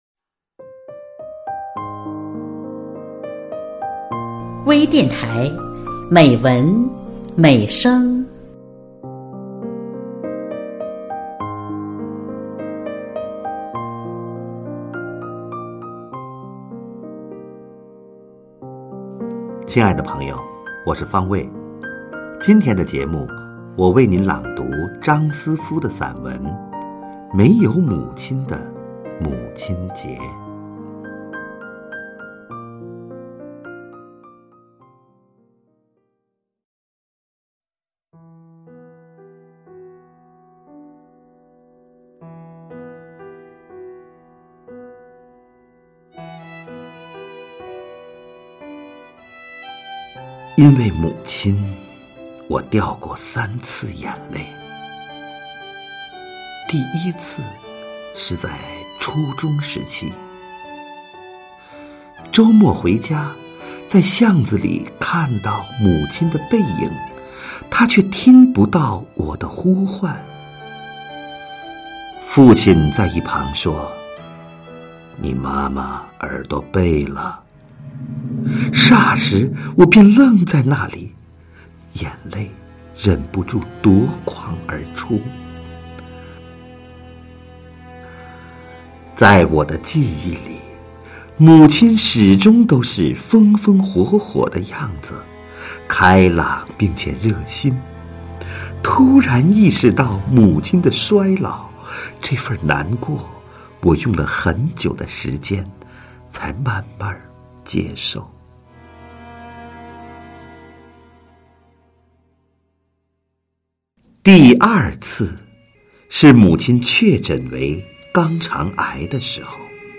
多彩美文  专业诵读
朗 诵 者